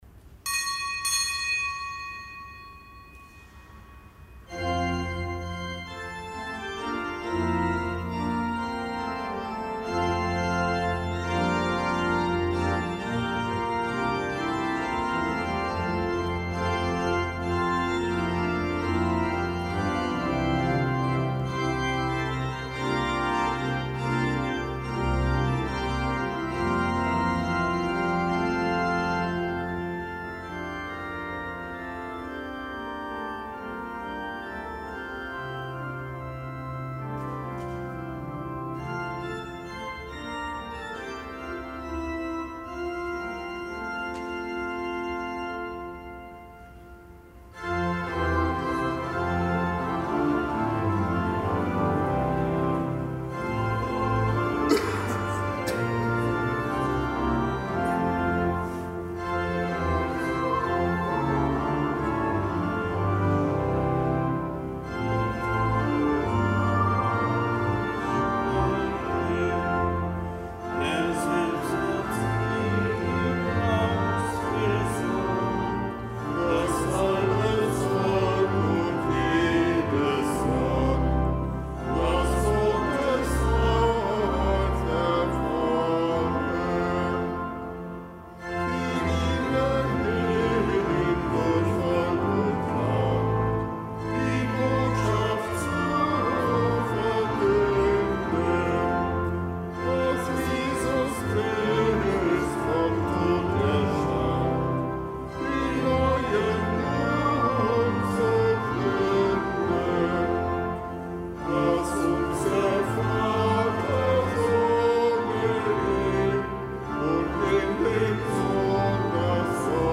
Kapitelsmesse am Fest des Heiligen Jakobus
Kapitelsmesse aus dem Kölner Dom am Fest des Heiligen Jakobus; Apostel.